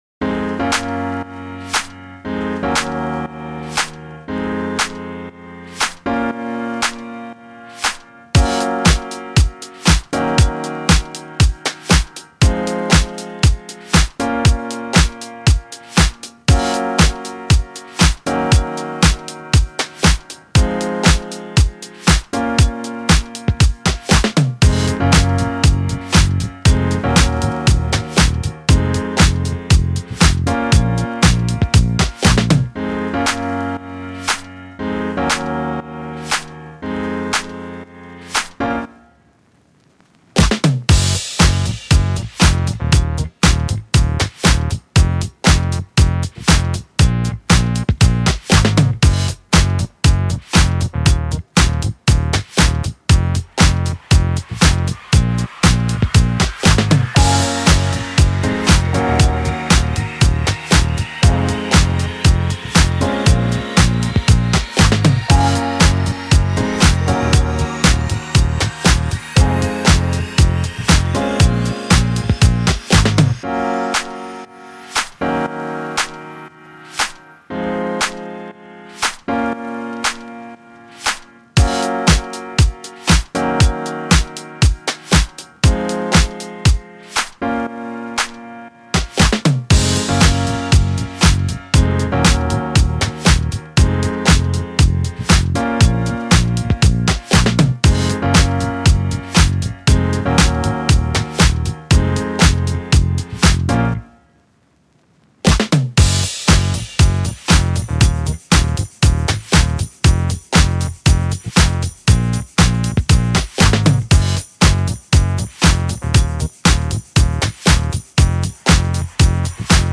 1-million-regnbuer-instrumental.mp3